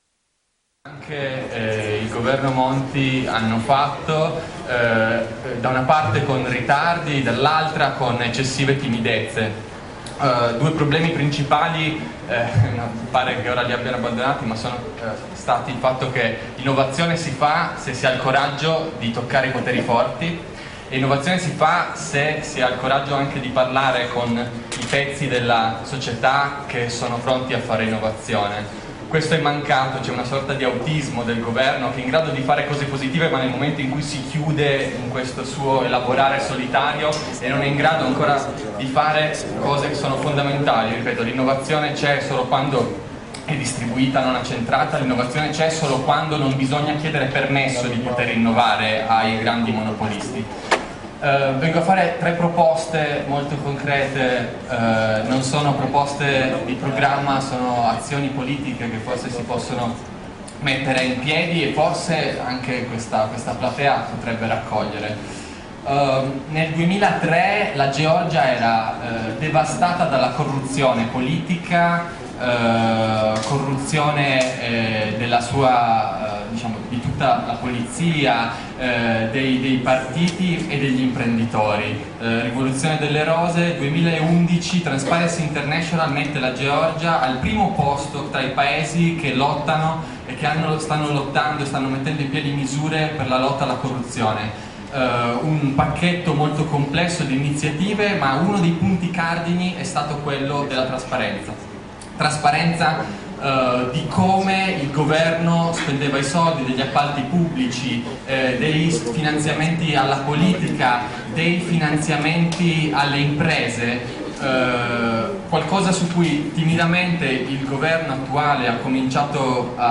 Intervento registrato in occasione della Convention di Zero Positivo tenutasi a Roma presso la Domus Talenti il 9 giugno 2012.